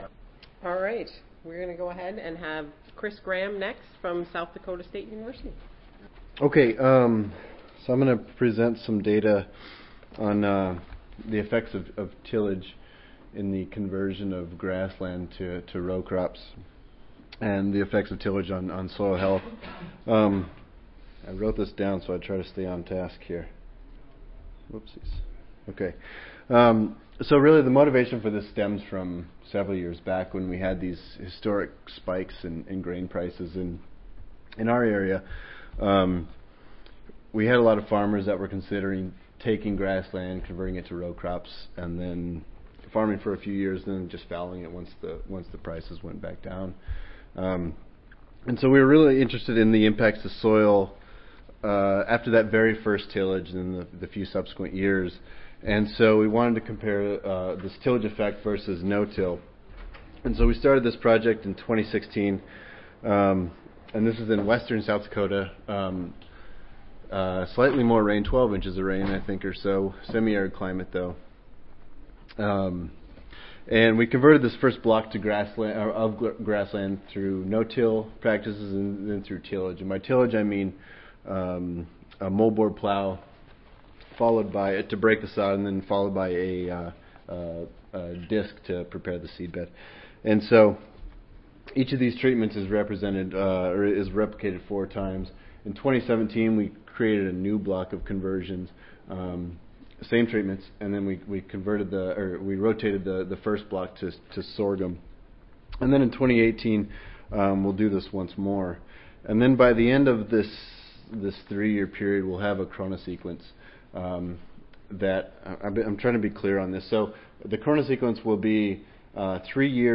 South Dakota State University Audio File Recorded Presentation